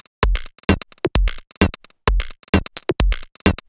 Alphatown1 130bpm.wav